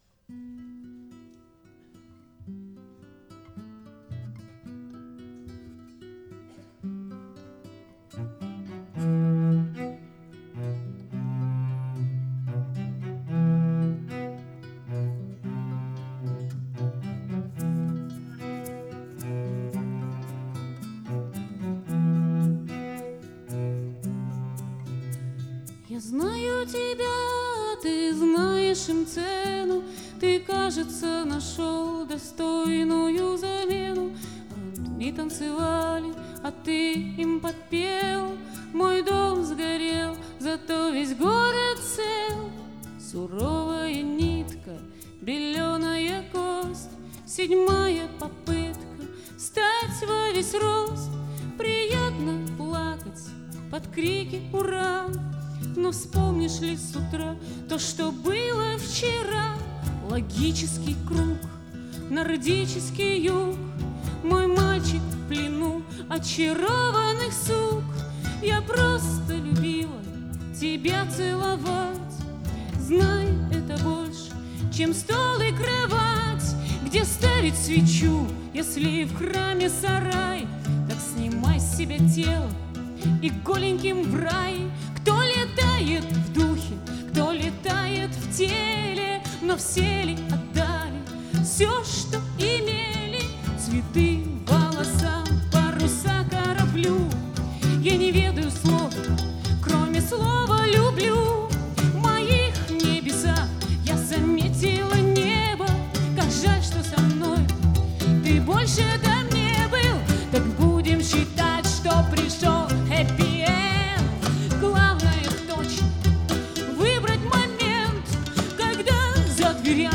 Концертный двойник, стиль — акустика.
голос, гитара
виолончель, голос
флейты, гитары, перкуссия, голос
мандолина, голос